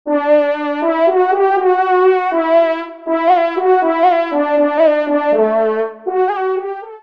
FANFARE
Pupitre de Chant